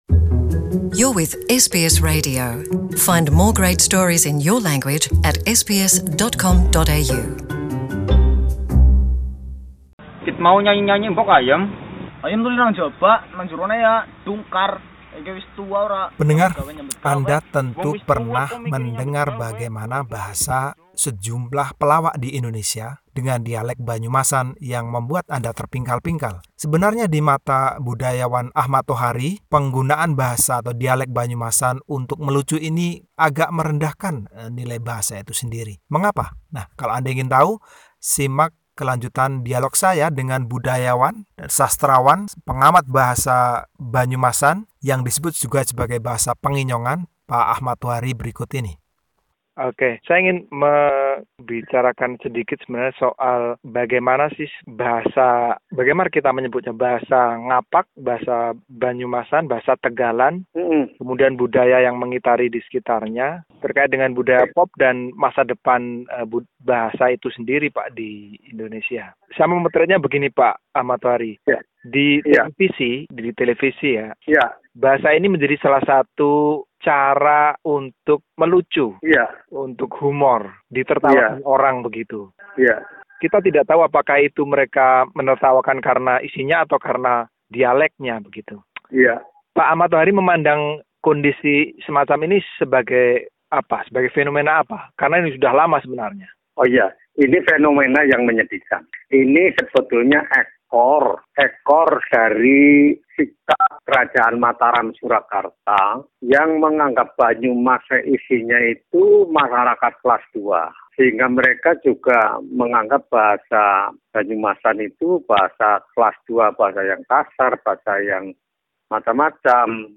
Budayawan Ahmad Tohari berbincang mengenai Bahasa Banyumasan, termasuk rasa kurang percaya diri penuturnya dan upaya yang dilakukan untuk kembali menjadikan warga Banyumas bangga pada dialek asli mereka.